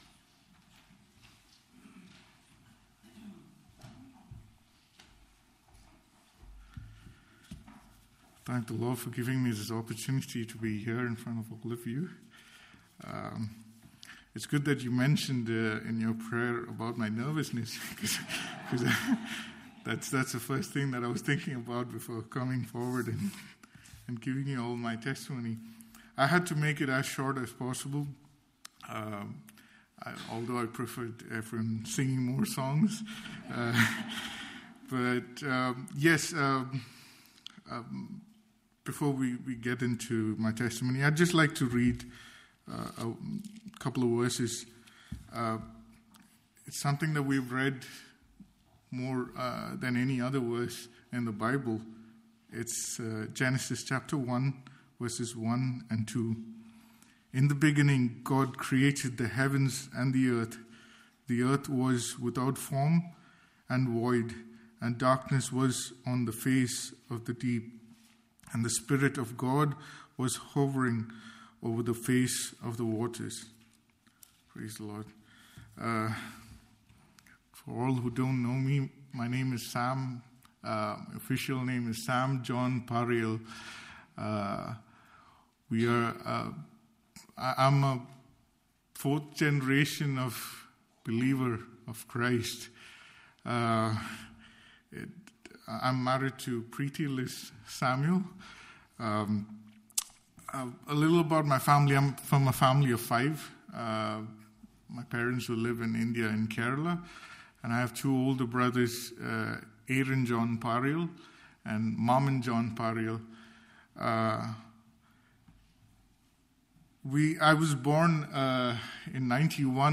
Passage: 2 Cor 5:11 to 6:2 Service Type: Family Bible Hour